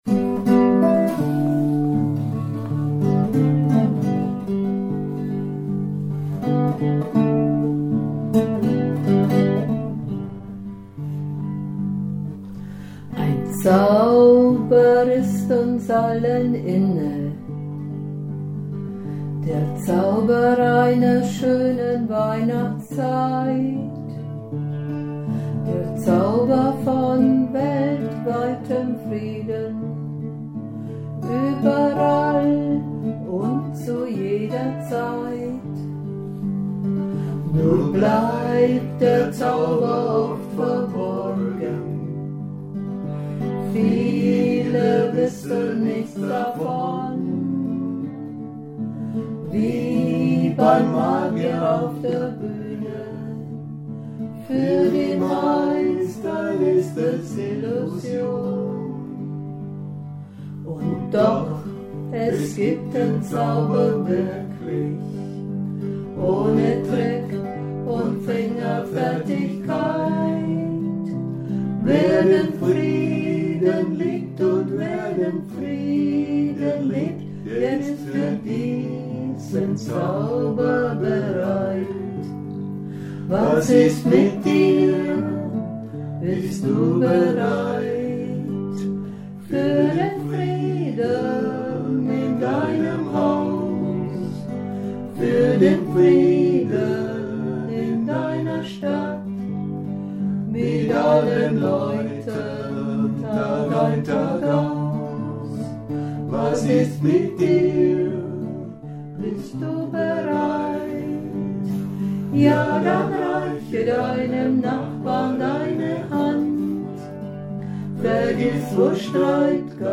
Dann mache ich mich daran, eine zweite Stimme zu schreiben und dann auch mitzusingen.
Ach ja, ich zupfe dazu die Gitarre.
Unsere Aufnahmen sind leider noch nicht professionell, wir lassen gelegentlich ein Aufnahmegerät bei Proben mitlaufen.